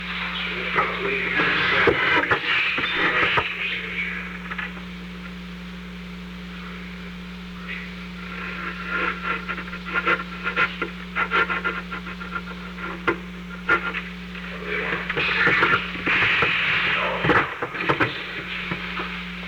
Secret White House Tapes
Conversation No. 532-19
Location: Oval Office
The President met with Alexander P. Butterfield.